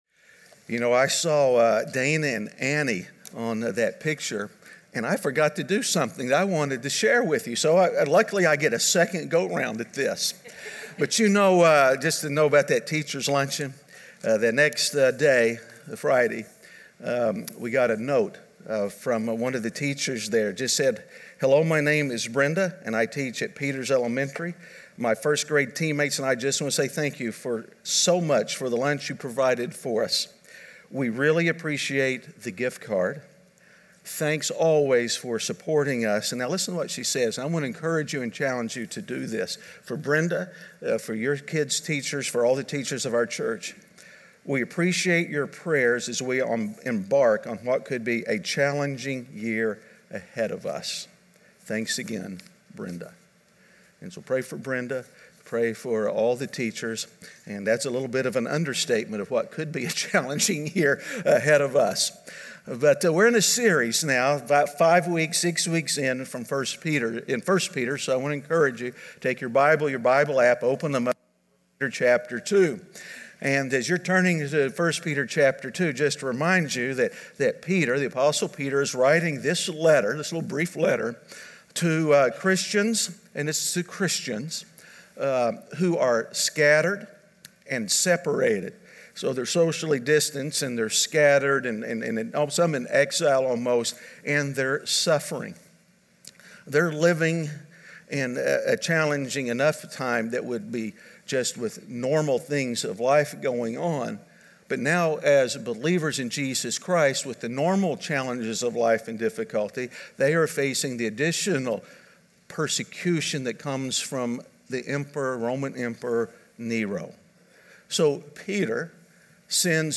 Standing Firm In Shaky Times (Week 5) - Sermon.mp3